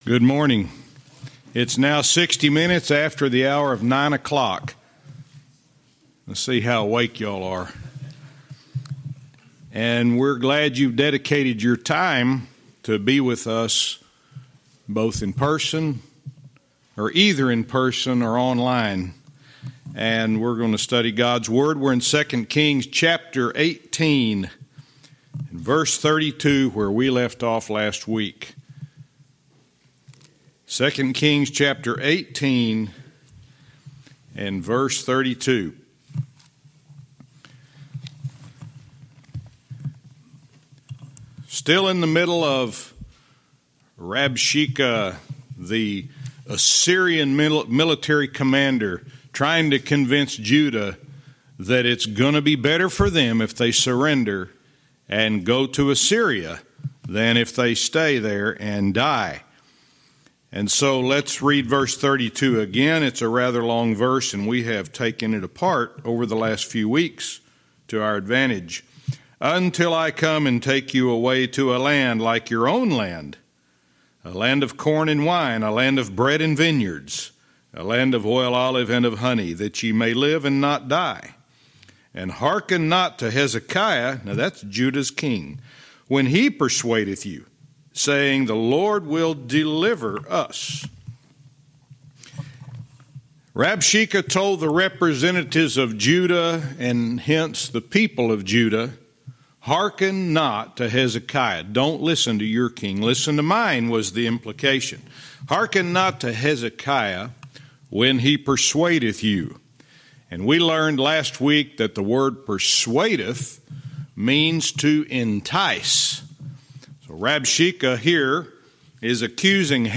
Verse by verse teaching - 2 Kings 18:32-33